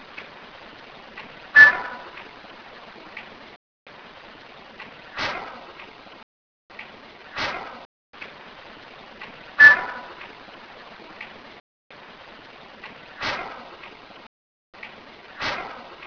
Loud Scrape 2 - Recorded in the same location as the previous EVPs, this weird noise sounds almost mechanical, but currently can not be explained.
Loud Scrape 2 has the original once and the NR twice. Again, the NR seems to make the scrape sound different.
Slowed down version - sounds like a typical squeeky hospital cart to me.
loudscrape2.wav